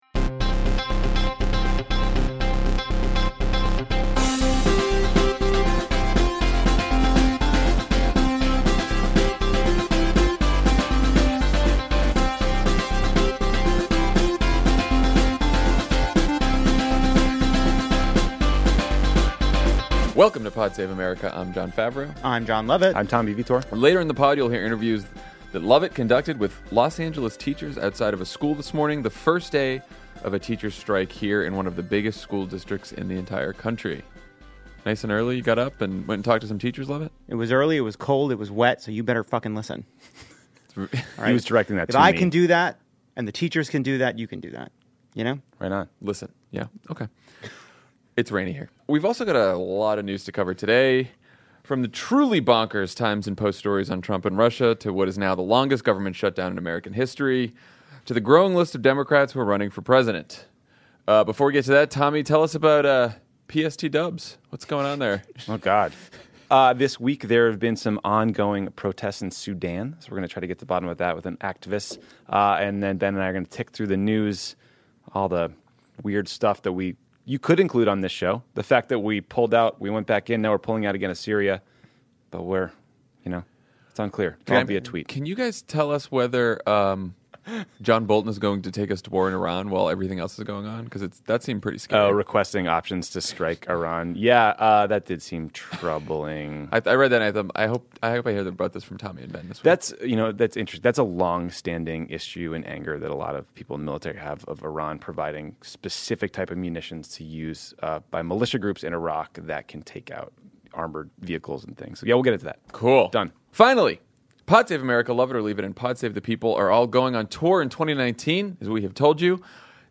Trump hides the details of his chats with Putin while the FBI investigates whether he’s compromised, the government shutdown becomes the longest in history, Julian Castro and Tulsi Gabbard announce their presidential campaigns, and Republicans might finally take action against white nationalist Steve King. Then Jon Lovett talks to Los Angeles teachers on the first day of their strike.